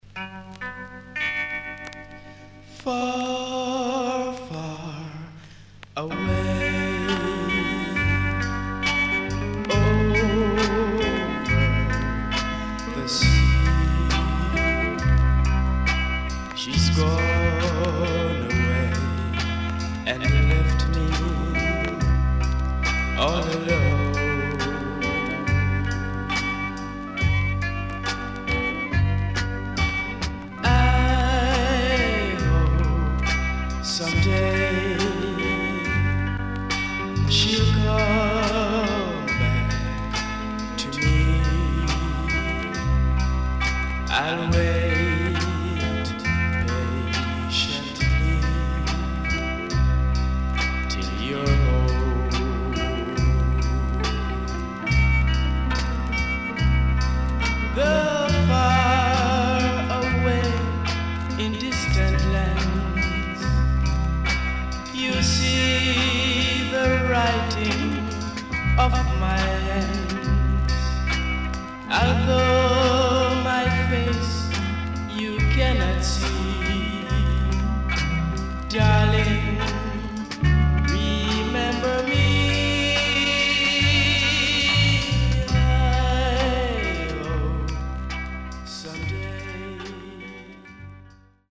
CONDITION SIDE A:VG(OK)
Early Reggae Vocal
SIDE A:少しチリノイズ、プチノイズ入ります。